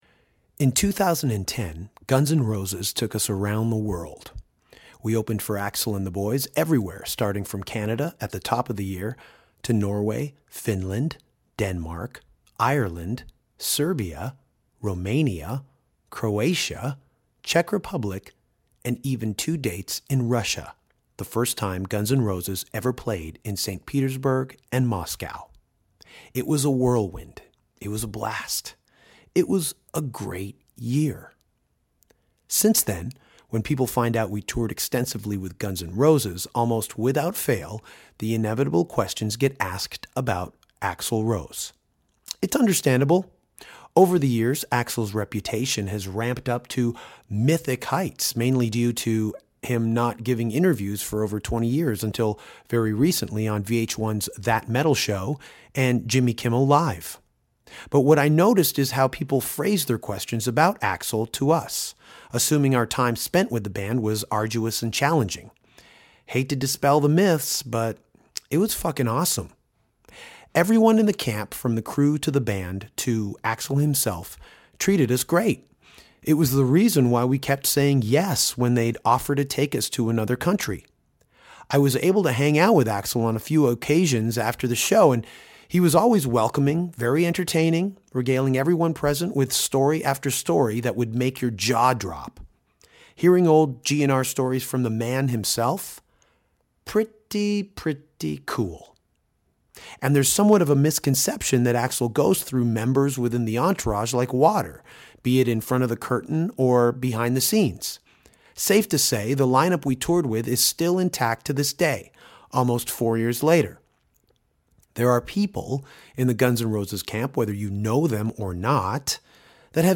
Danko corralled both Dizzy Reed and Richard Fortus, Guns N’ Roses & Dead Daisies members, during the Uproar Tour this past summer to chat about ZZ-Top, Billy Idol, Rihanna, joining Gun N’ Roses, wearing capes and bowling with Chris Holmes.